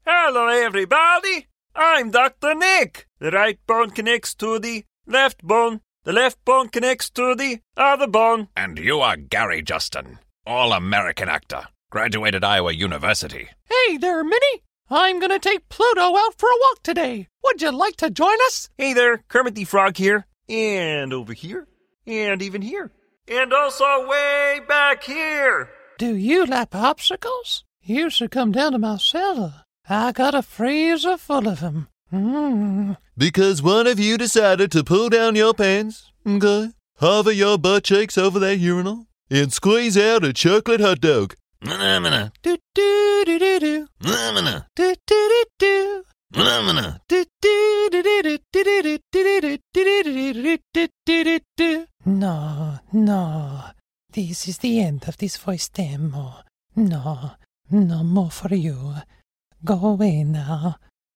Male
Sample Of Impersonations